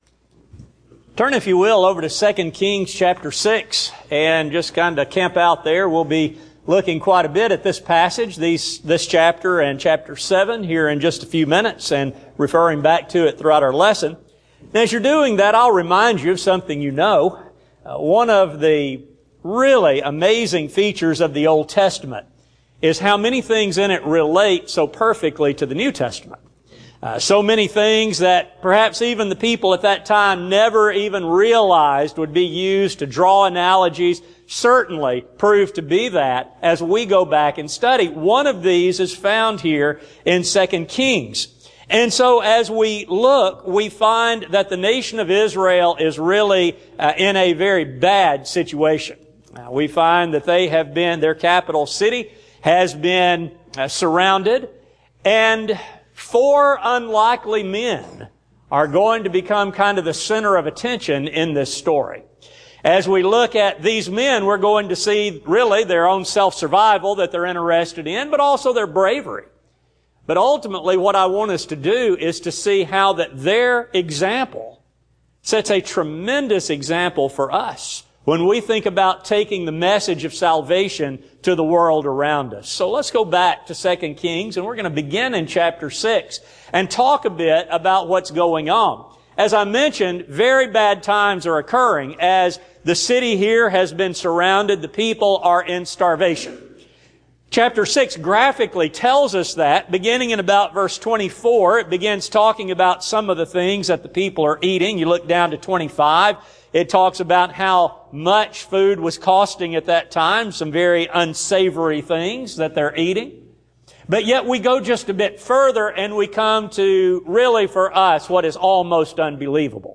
Service: Sun PM Type: Sermon